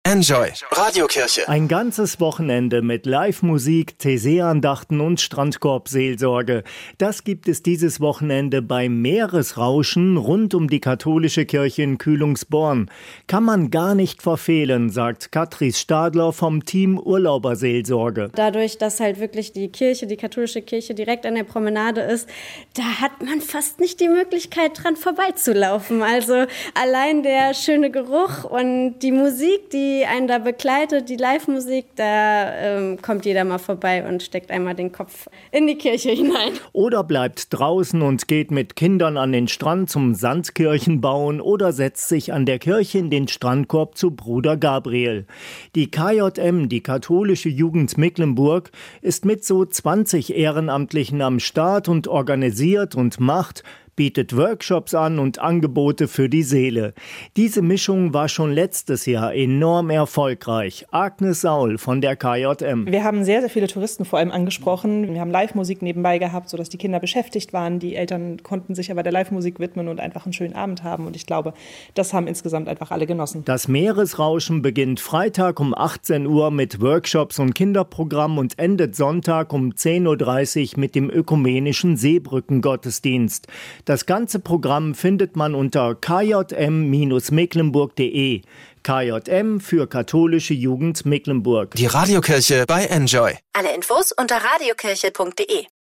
Der letzte Gottesdienst in der alten Christuskirche am 10. Juni 1971 kann hier nachgehört werden.